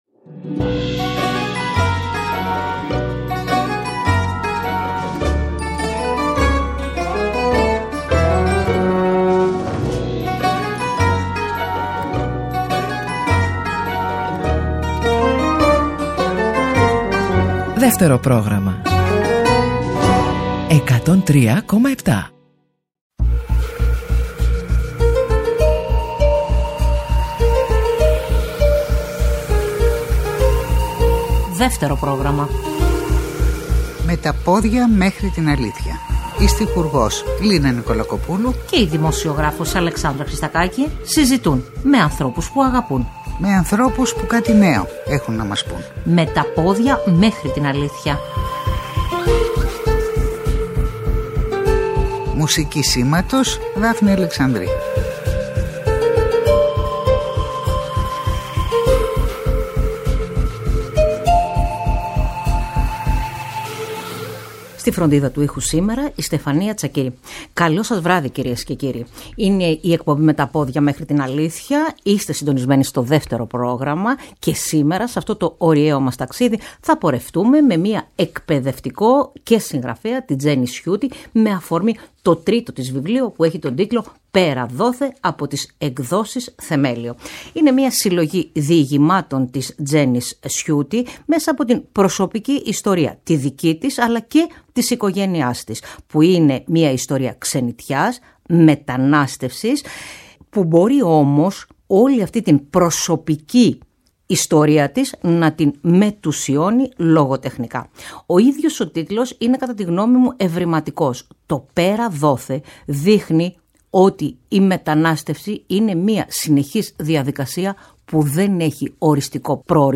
Συζήτησε με την στιχουργό Λίνα Νικολακοπούλου